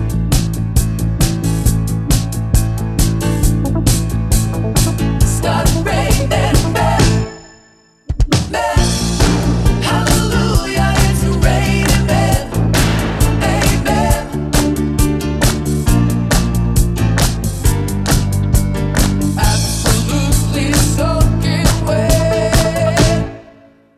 Three Semitones Down Pop (1980s) 3:42 Buy £1.50